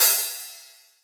DDWV OPEN HAT 3.wav